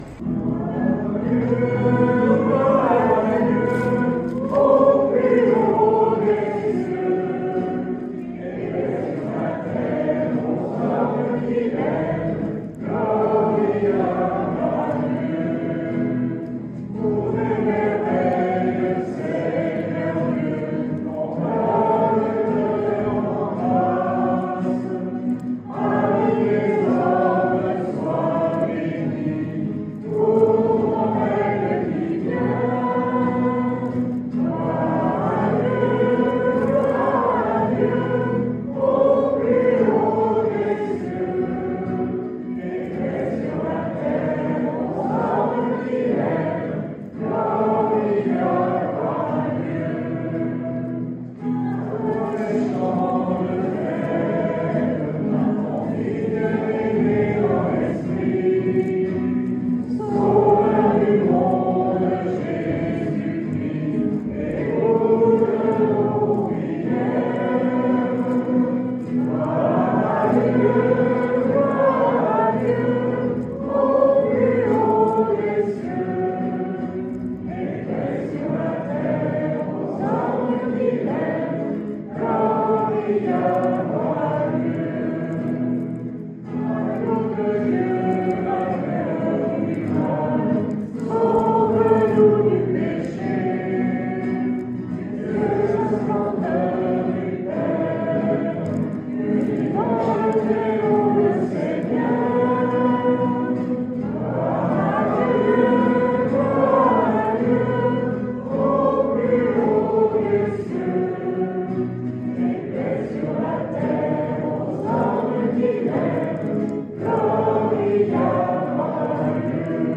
La dernière messe
la messe de désacralisation